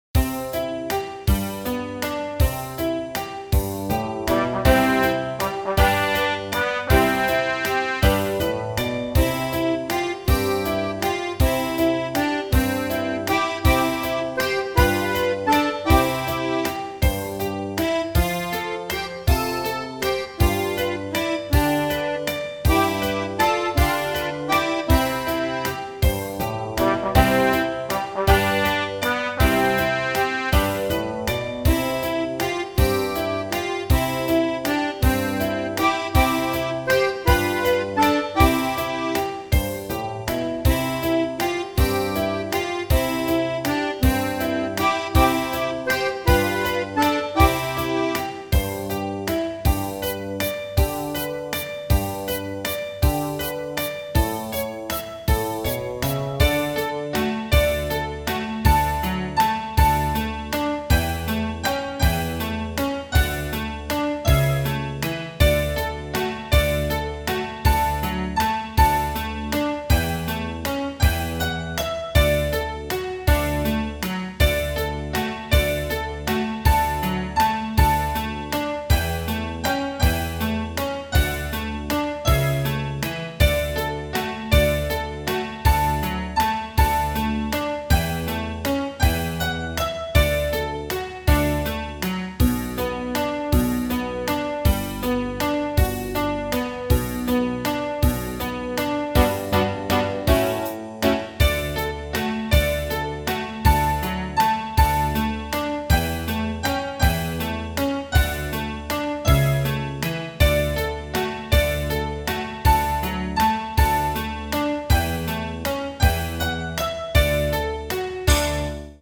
walz1.mp3